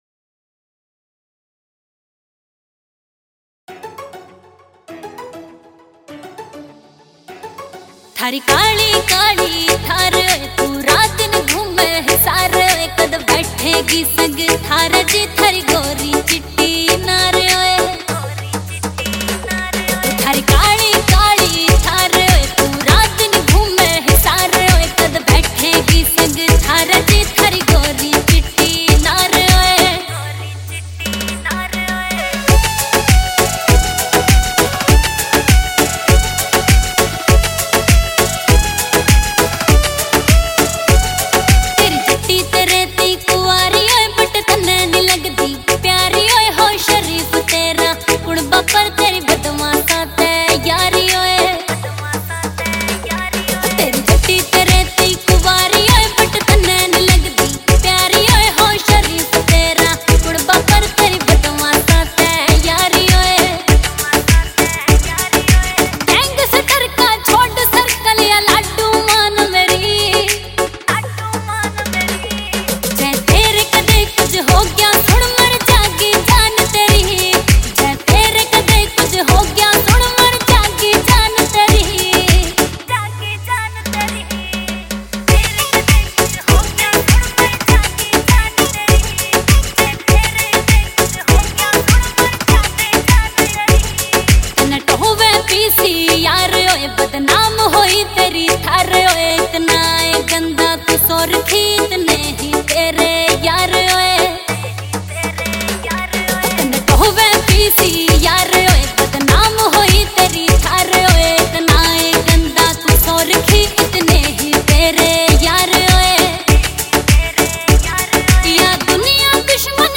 Releted Files Of New Haryanvi Song 2025